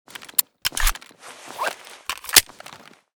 beretta_reload.ogg